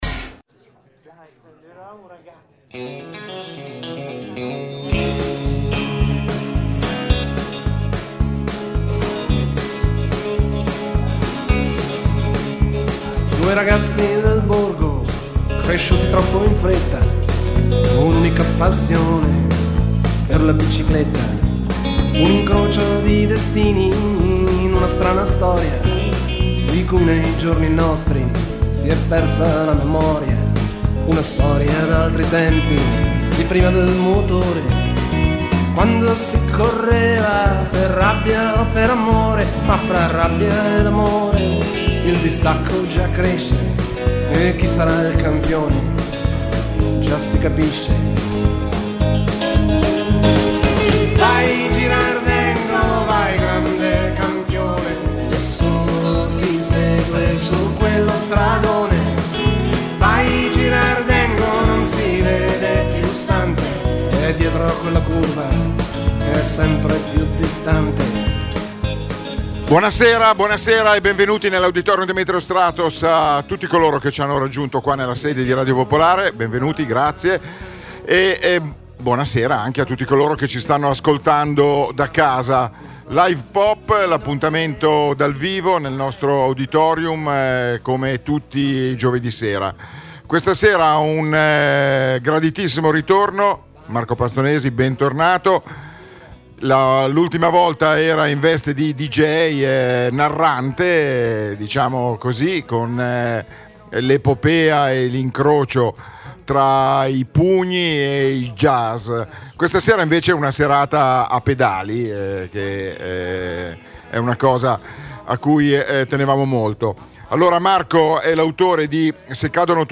Per una simile serata non poteva che esserci lui come ospite d’onore: Dino Zandegù.